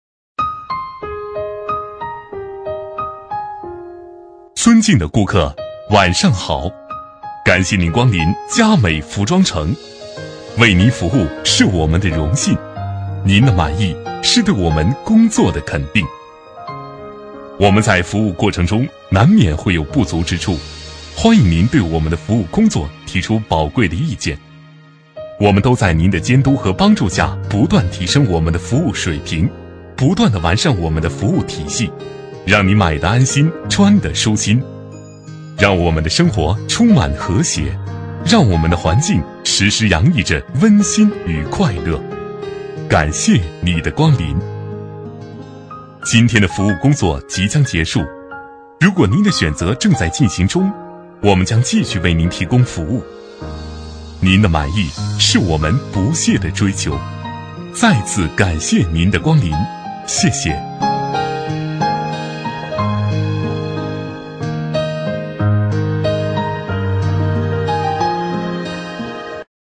B类男01
【男1号抒情】店铺温馨播报